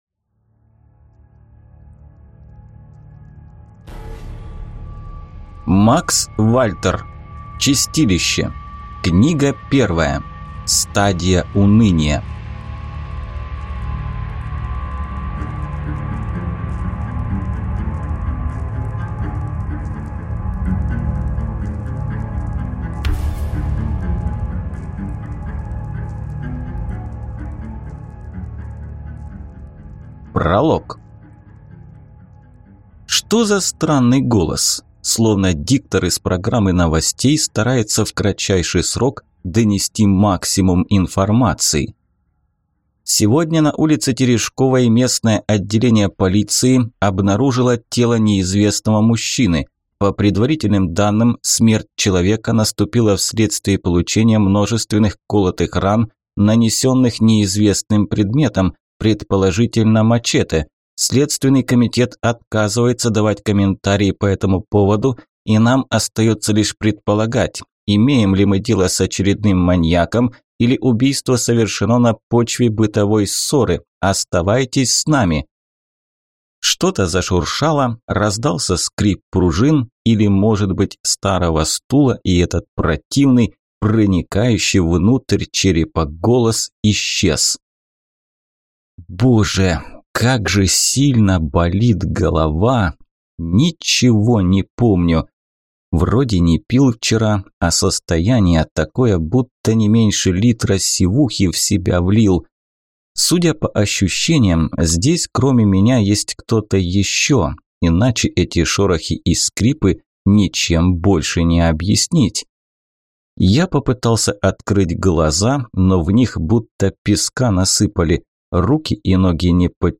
Аудиокнига Стадия Уныния | Библиотека аудиокниг